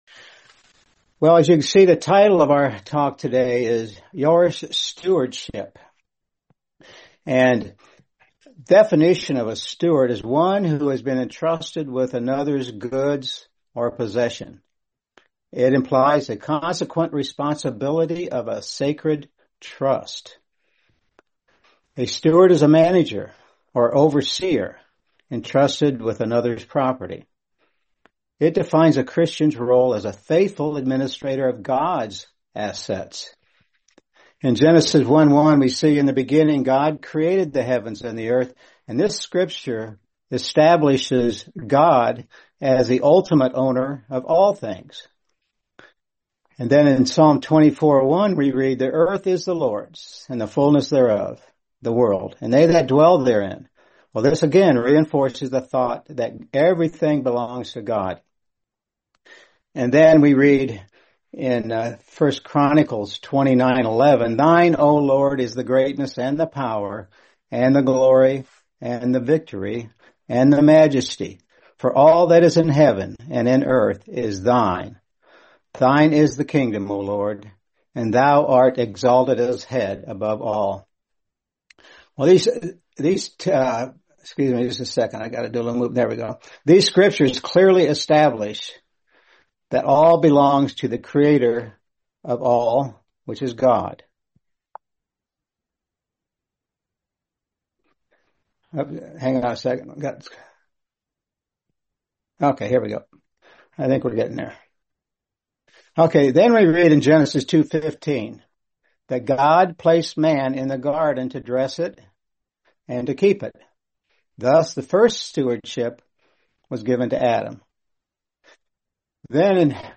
Series: 2026 North Seattle Convention